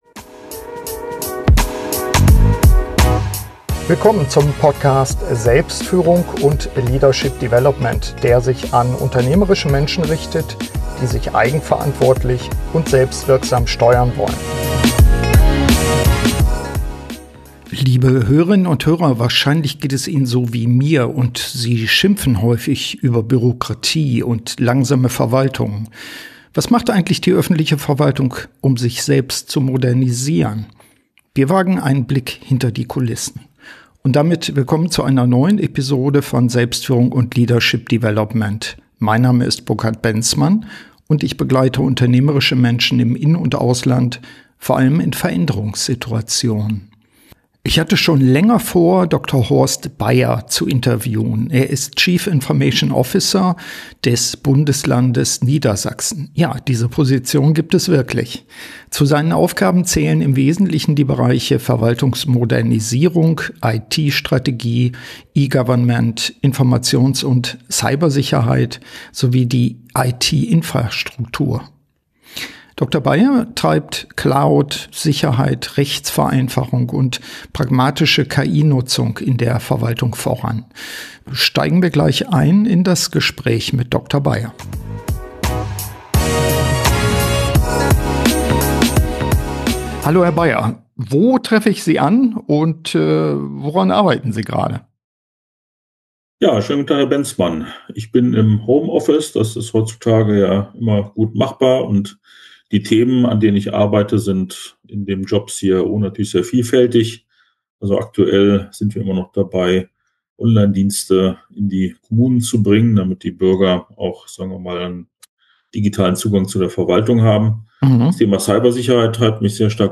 Ist der Einsatz von KI in der öffentlichen Verwaltung hilfreich oder bringt uns das noch mehr Bürokratie und Kontrolle? Ich spreche mit dem CIO von Niedersachen, Dr. Horst Baier, ausführlich über die Ansätze, die Verwaltung zu modernisieren.